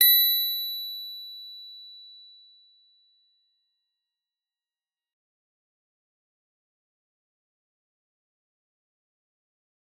G_Musicbox-B6-f.wav